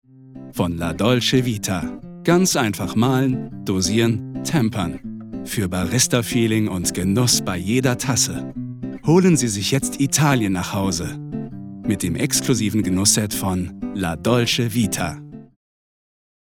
freundlich, sinnlich